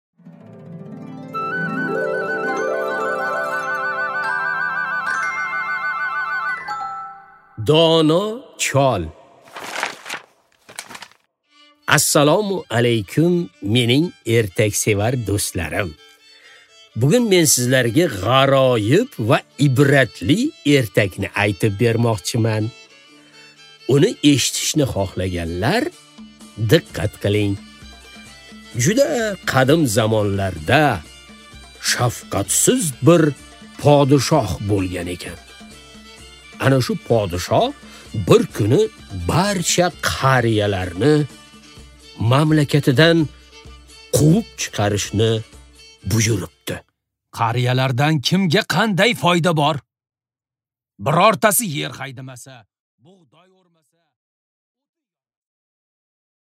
Аудиокнига Dono chol